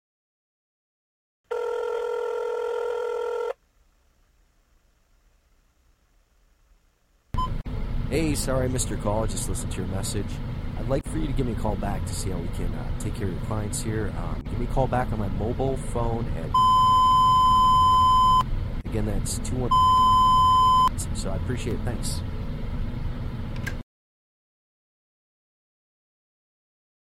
The Voice Lead Generator drops your promo message right into voicemail boxes, no cold calling needed!